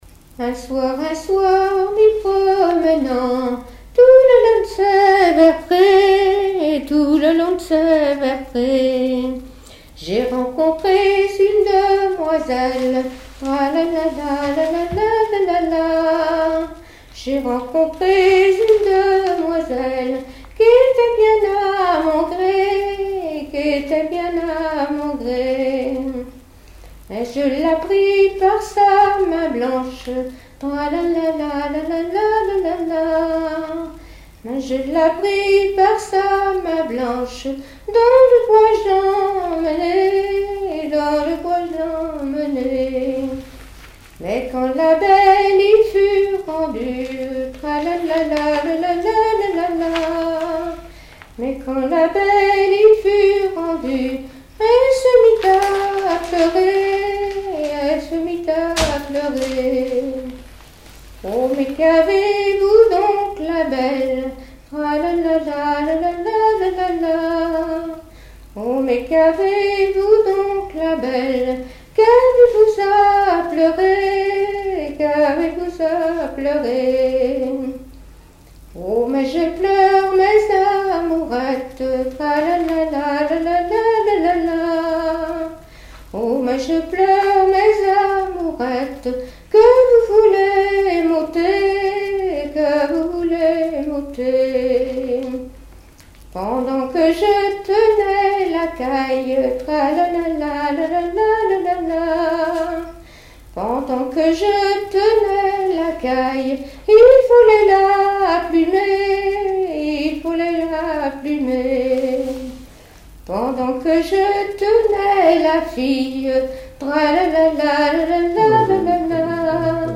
Genre laisse
Témoignages et chansons
Catégorie Pièce musicale inédite